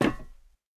immersive-sounds / sound / footsteps / rails / rails-06.ogg
rails-06.ogg